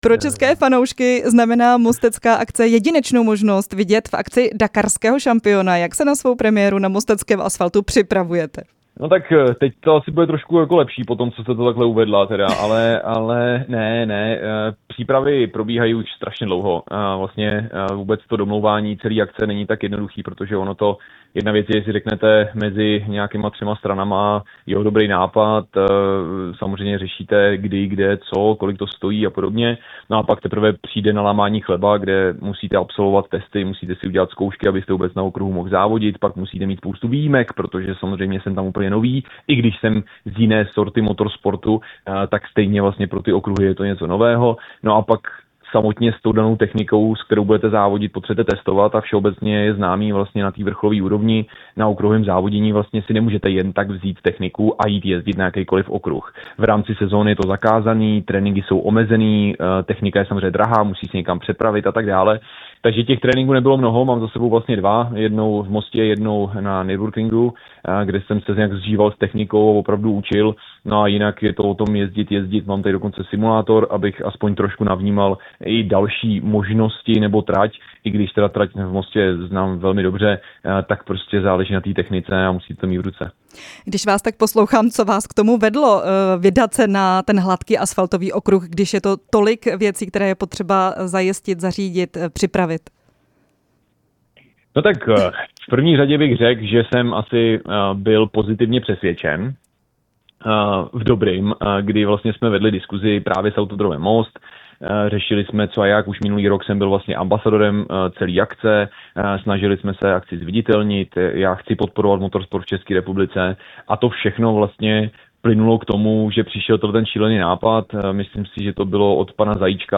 Rozhovor s automobilovým závodníkem Martinem Macíkem